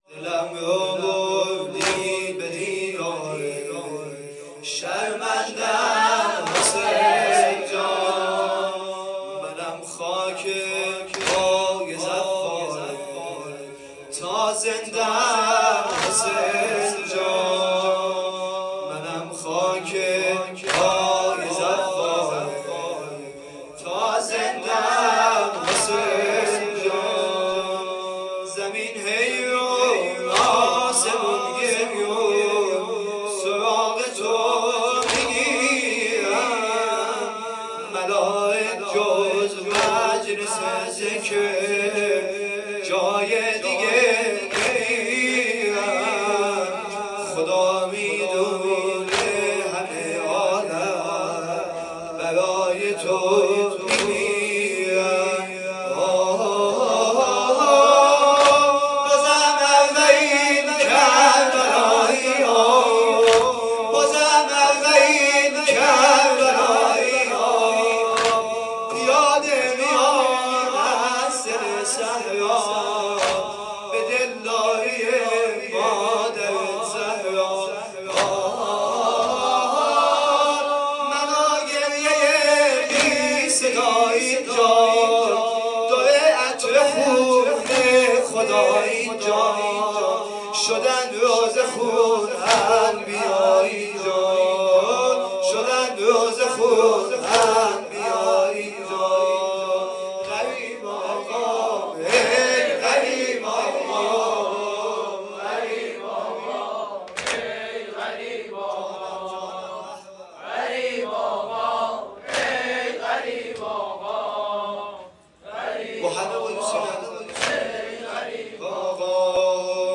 شب ۹ صفر ۹۷